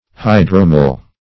Hydromel \Hy"dro*mel\, n.